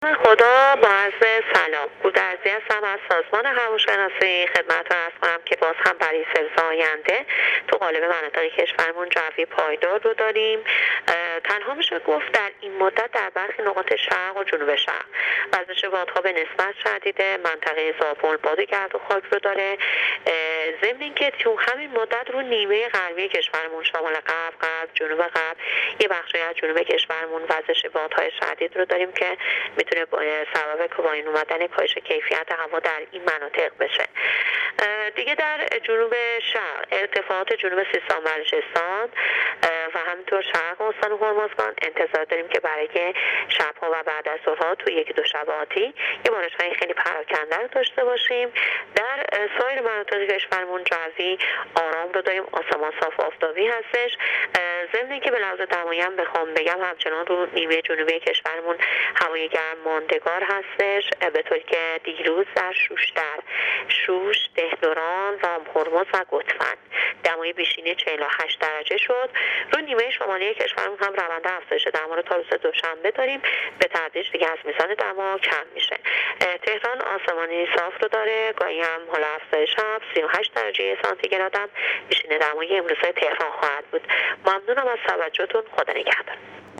دریافت فایل weather با حجم 1 MB برچسب‌ها: هواشناسی - گزارش رادیو تلویزیون